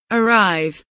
arrive kelimesinin anlamı, resimli anlatımı ve sesli okunuşu